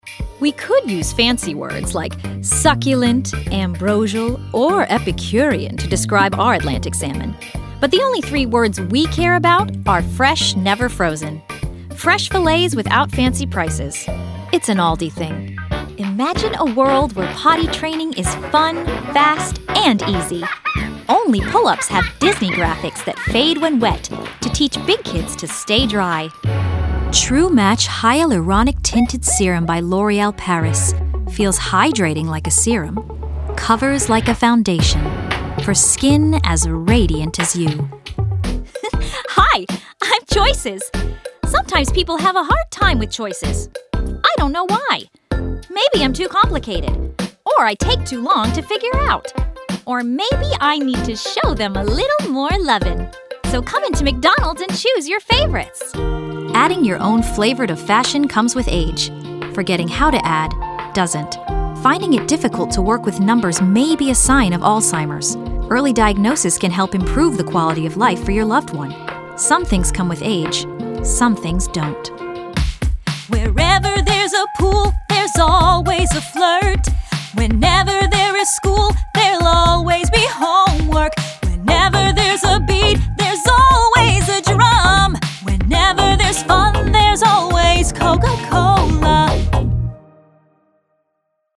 VO Artist and Singer with a clear, crisp and warm voice
Commercial Demo